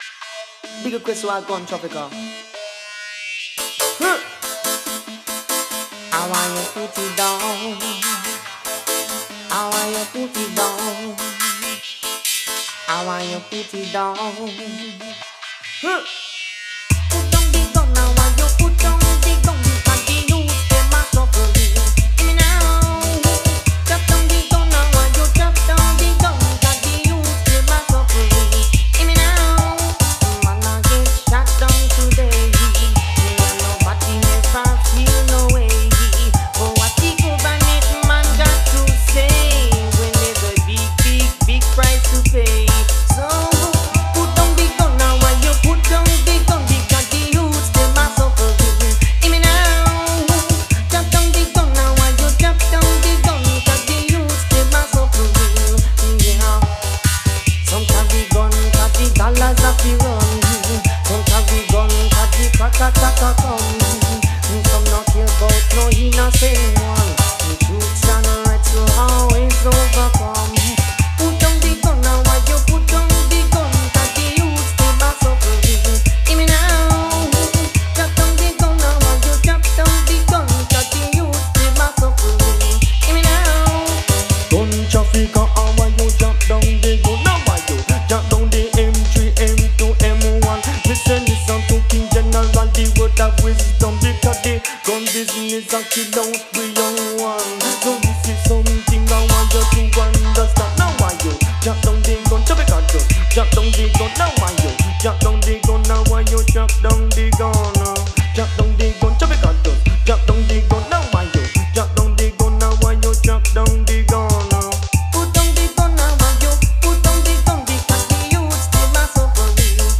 Recorded at Conscious Sounds Studio London UK